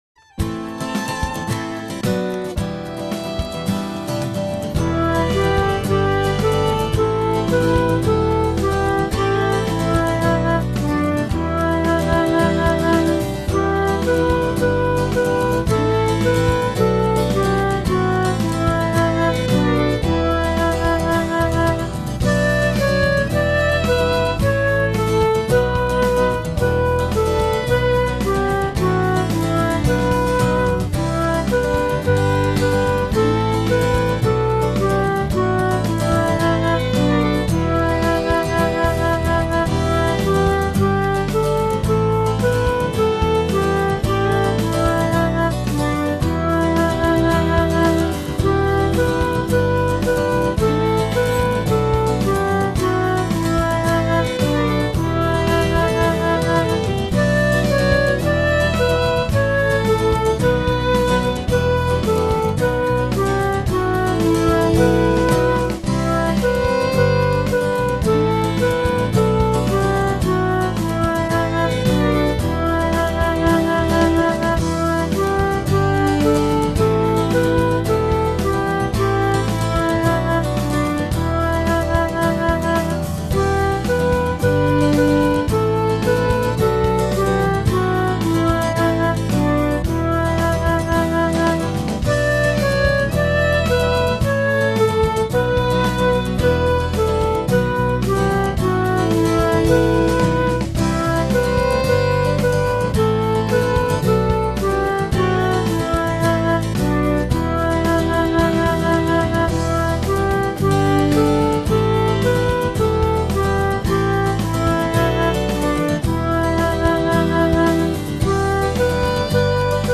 My fussy backing: